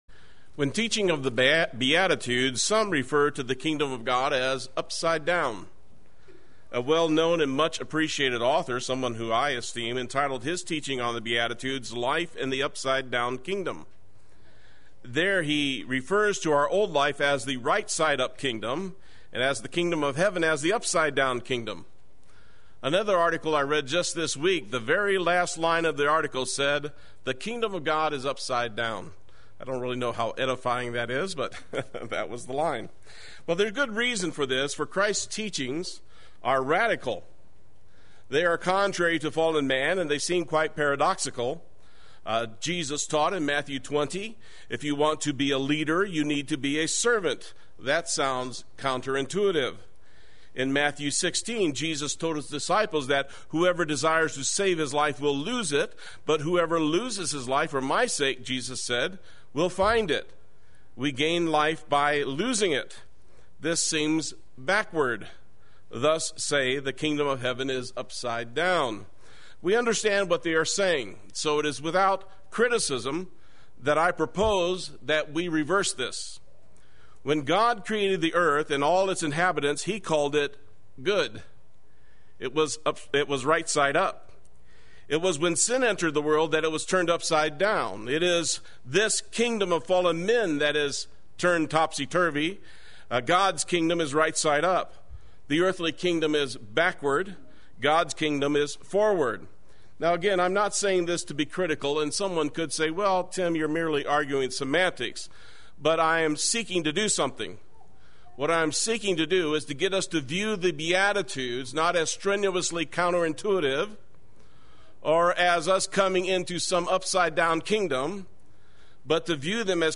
Play Sermon Get HCF Teaching Automatically.
Two Blesseds Sunday Worship